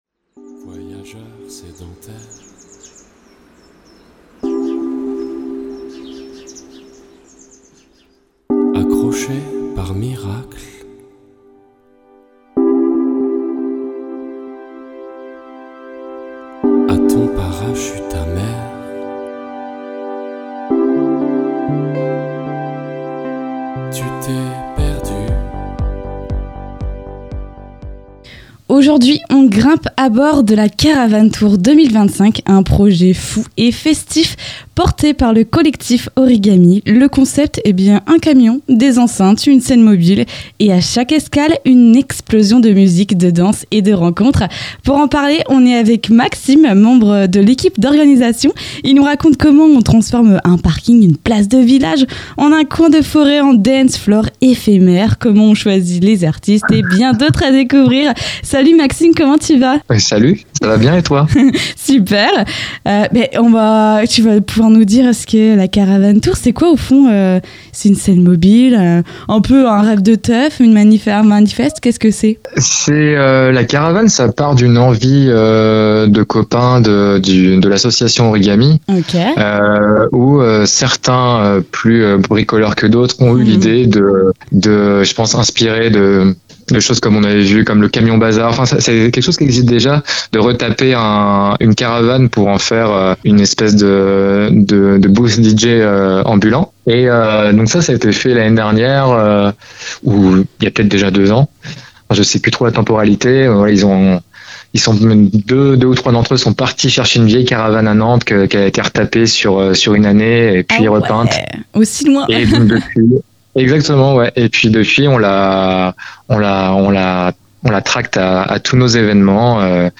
Pour en savoir plus sur cet rencontre n'hésitez pas à écouter jusqu'au bout l'interview et à partir vous aussi à la rencontre de la caravane tour 2025 !